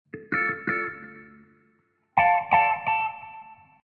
Descarga de Sonidos mp3 Gratis: guitarra.